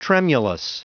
Prononciation du mot tremulous en anglais (fichier audio)
Prononciation du mot : tremulous